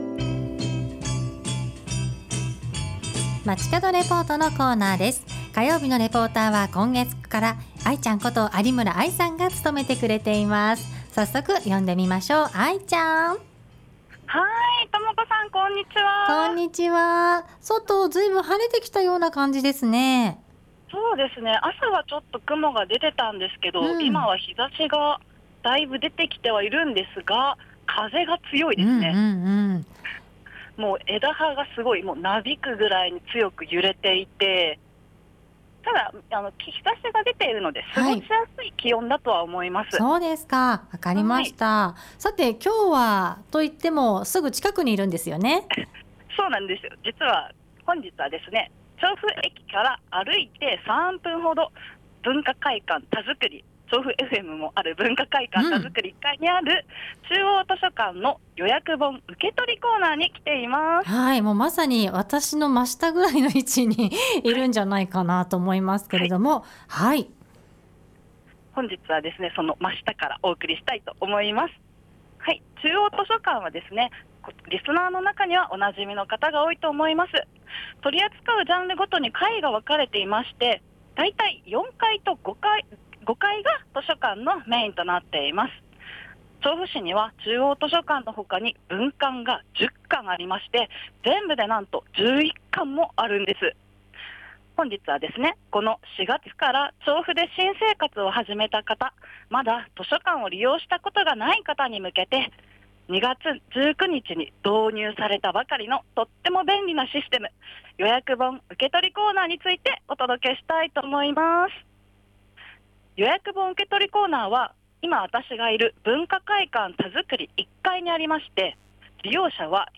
午後のカフェテラス 街角レポート
本日は調布市民お馴染み、中央図書館の予約本受け取りコーナーからお届けしました！
今回はレポート中に実際に利用してみました！